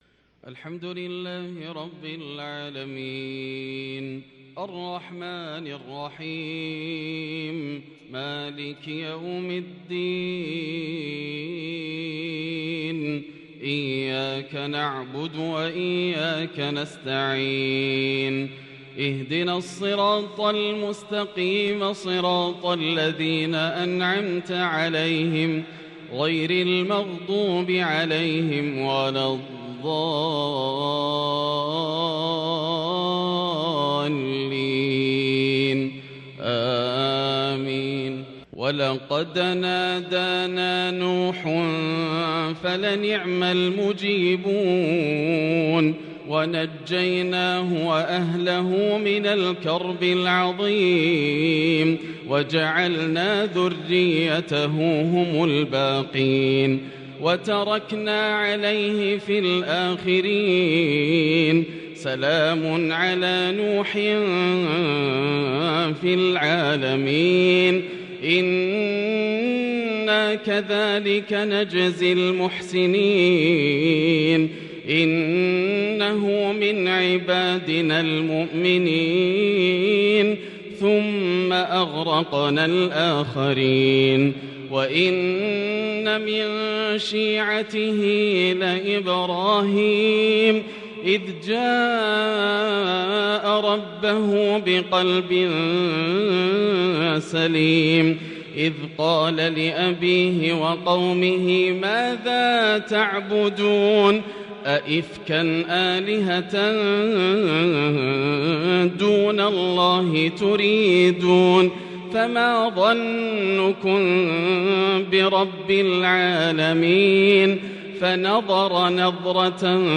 " وفديناه بذبح عظيم" أجهش من في الحرم بالبكاء من هذه التلاوة العراقية الآسرة 11ذو الحجة 1443هـ > تلاوات عام 1443هـ > مزامير الفرقان > المزيد - تلاوات الحرمين